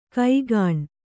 ♪ kaigāṇ